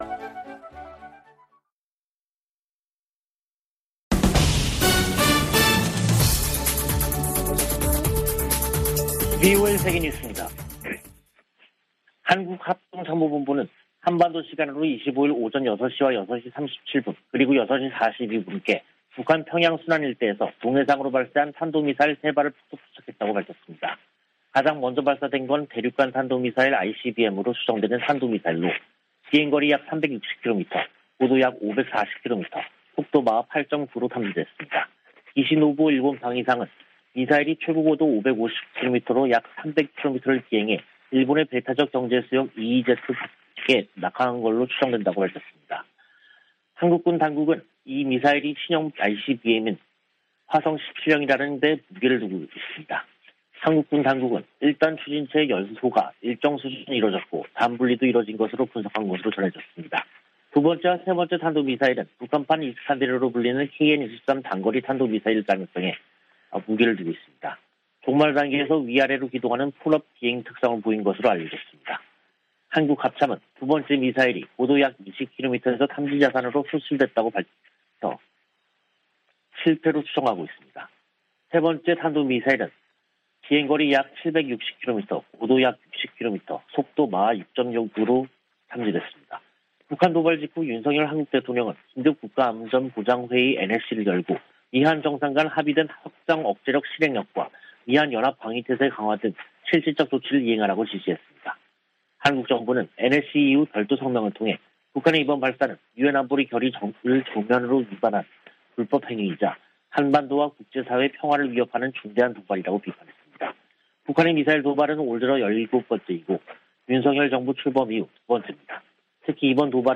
VOA 한국어 간판 뉴스 프로그램 '뉴스 투데이', 2022년 5월 25일 2부 방송입니다. 북한이 ICBM을 포함한 탄도미사일 3발을 동해상으로 발사했습니다. 미-한 군 당국은 미사일 실사격과 전투기 훈련 등으로 공동 대응했습니다. 미 국무부는 북한의 핵실험 등 추가 도발 가능성을 여전히 우려하고 있으며 적절한 책임을 물릴 것이라고 밝혔습니다.